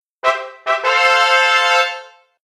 trumpet.ogg